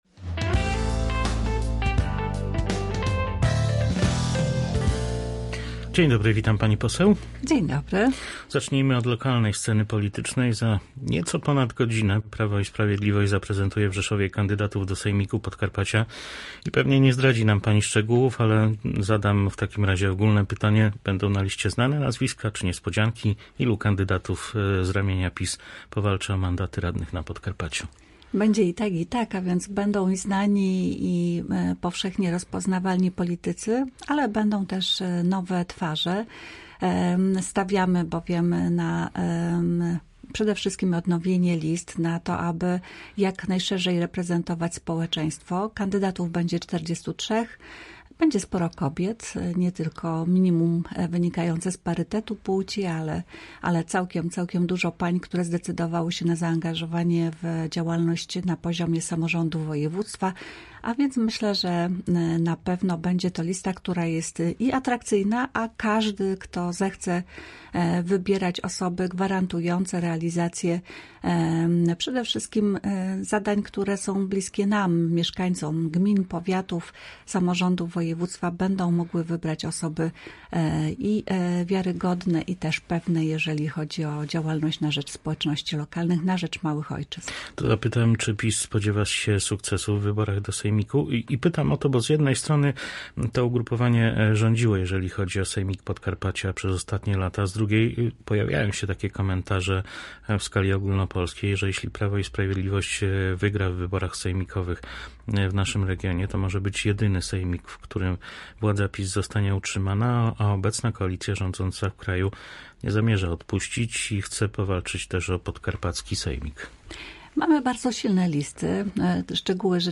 Kandydaci Prawa i Sprawiedliwości do Sejmiku Podkarpacia to zarówno znane nazwiska, jak też i nowe twarze, jest wśród nich również sporo kobiet – tak przedstawione przez PiS listy sejmikowe komentuje nasz dzisiejszy gość posłanka Ewa Leniart.